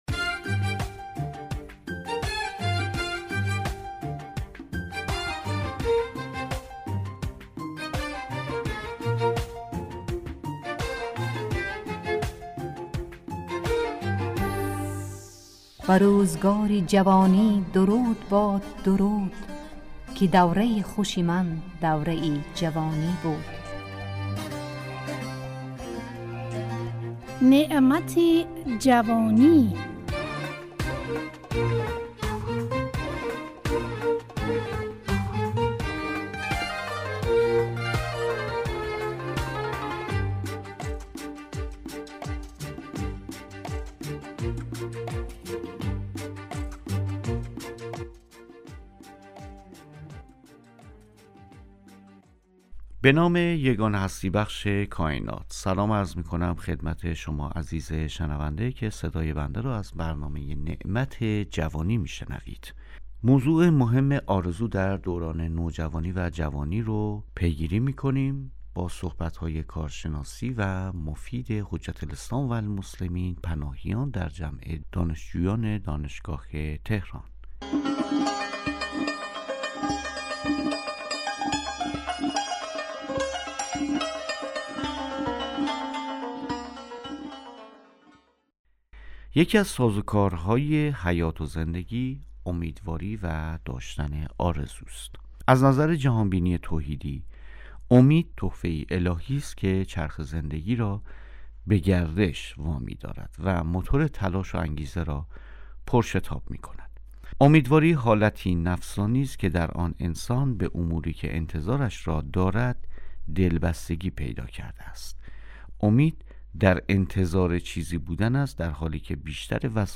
نعمت جوانی، برنامه ای از گروه اجتماعی رادیو تاجیکی صدای خراسان است که در آن، اهمیت این دوران باشکوه در زندگی انسان مورد بررسی قرار می گیرد.